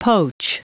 Prononciation du mot poach en anglais (fichier audio)
Prononciation du mot : poach